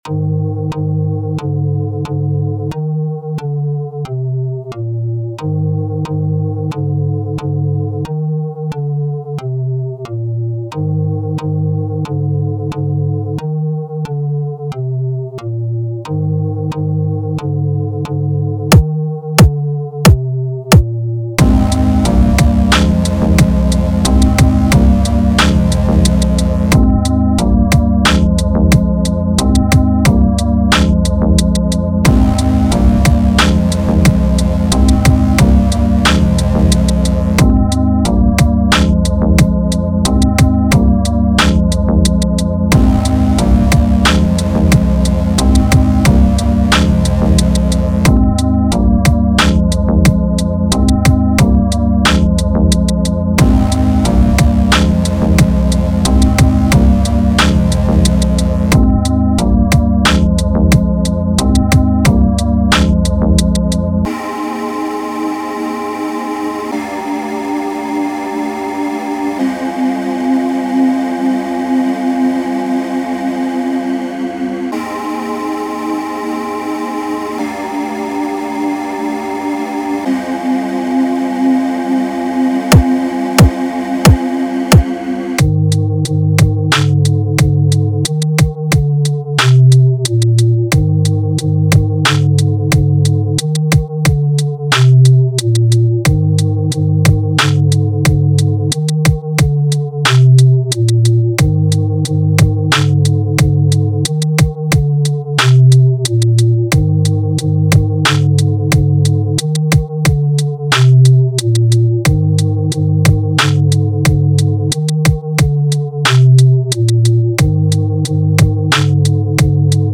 Feedback zu EDM track mit hip hop einflüssen.